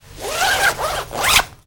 Cloth Rain Coat Zipper Sound
household